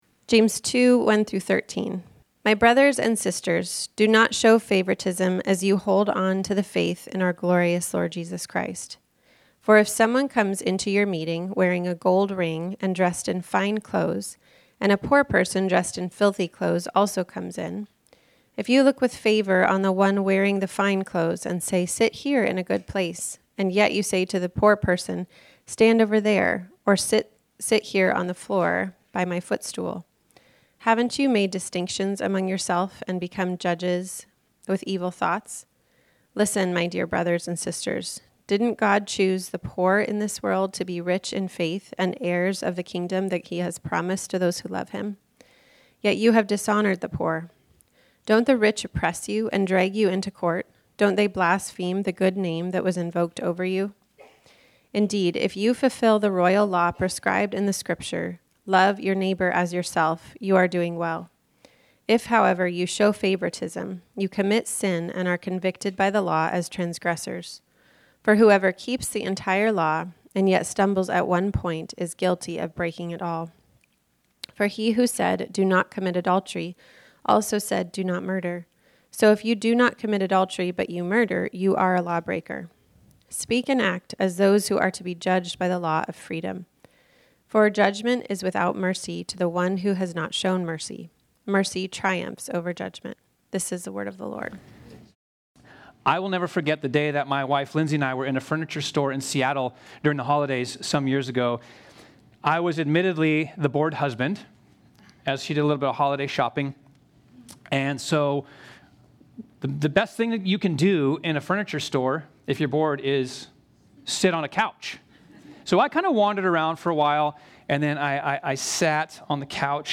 This sermon was originally preached on Sunday, October 19, 2025.